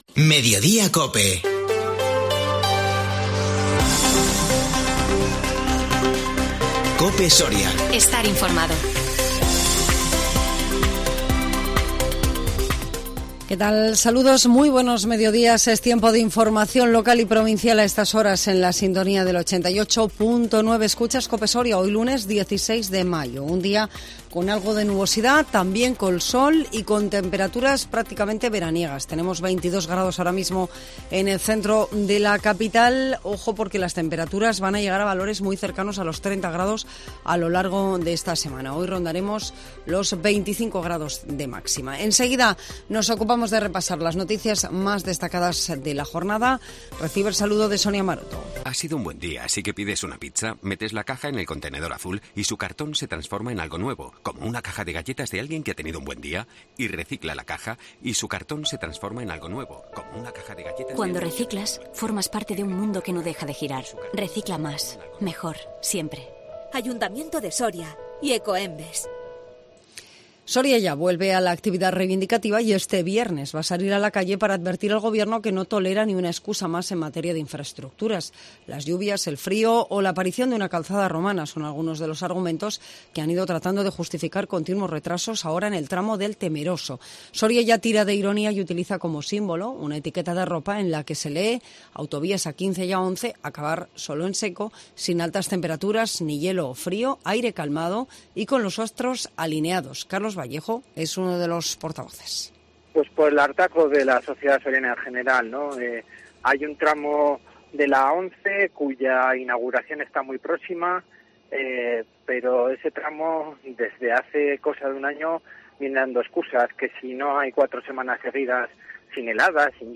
INFORMATIVO MEDIODÍA COPE SORIA 16 MAYO 2022